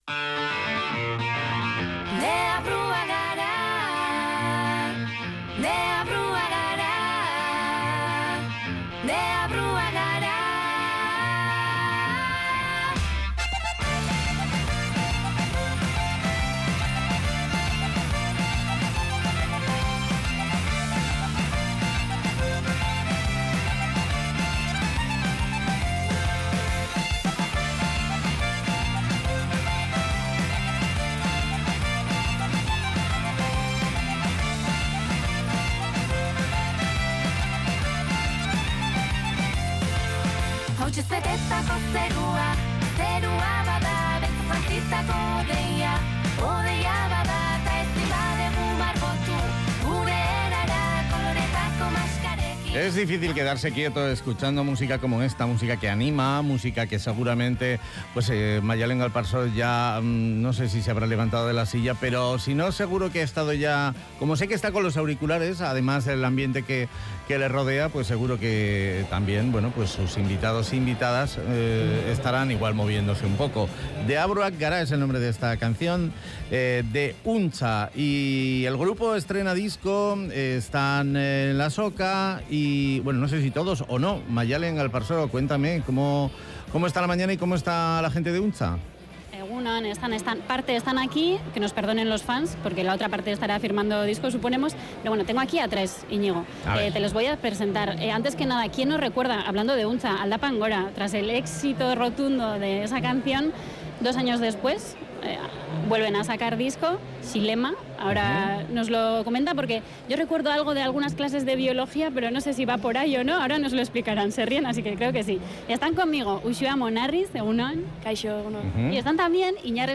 Entrevista desde Durangoko azoka